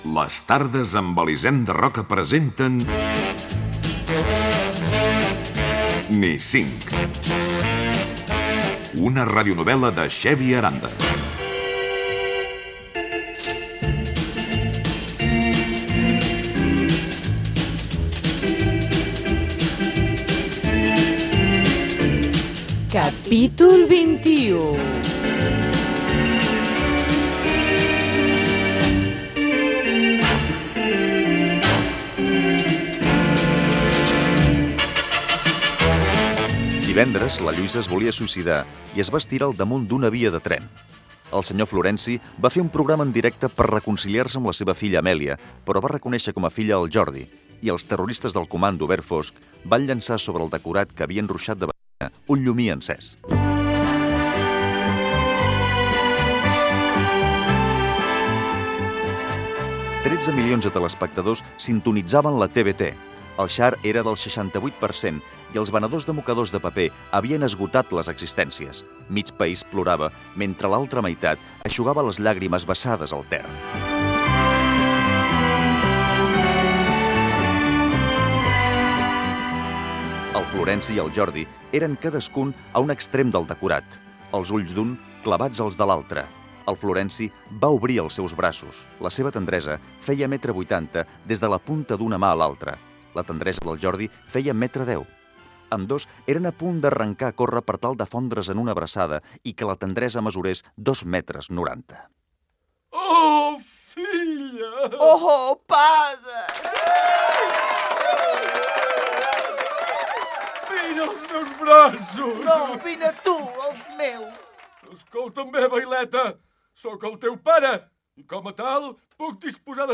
radionovel·la
Careta de l'espai i episodi Gènere radiofònic Entreteniment Data emissió 1998-02-23 Banda FM Localitat Barcelona Comarca Barcelonès Durada enregistrament 13:03 Idioma Català Notes Fragment extret de l'arxiu sonor de COM Ràdio.